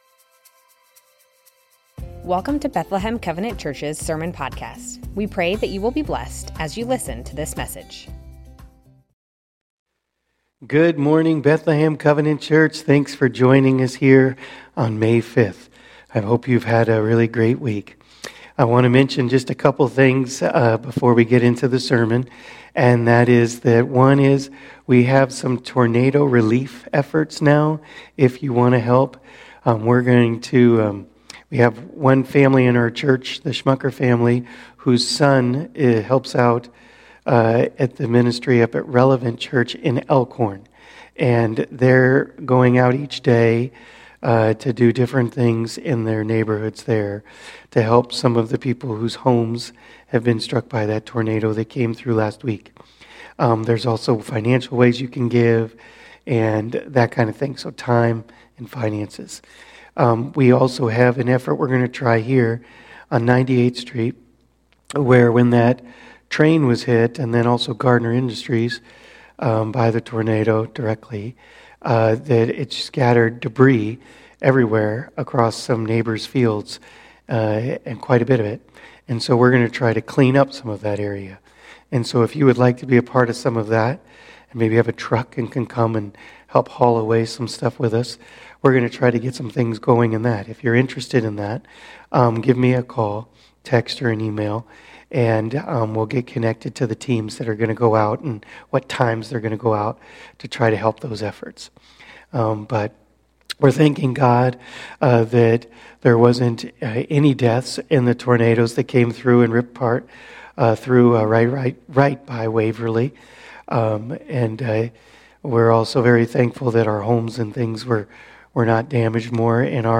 Bethlehem Covenant Church Sermons James 1:13-18 - Living Faith May 05 2024 | 00:36:56 1x 00:00 / 00:36:56 Subscribe Share Spotify RSS Feed Share Link Embed